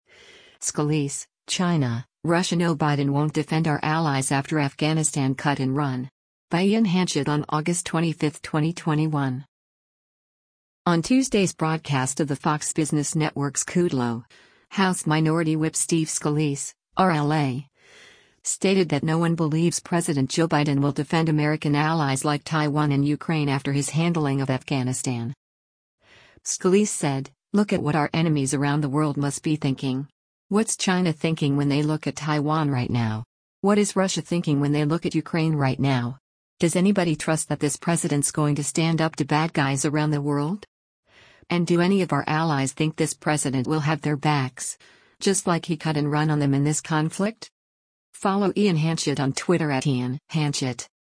On Tuesday’s broadcast of the Fox Business Network’s “Kudlow,” House Minority Whip Steve Scalise (R-LA) stated that no one believes President Joe Biden will defend American allies like Taiwan and Ukraine after his handling of Afghanistan.